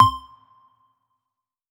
beep.wav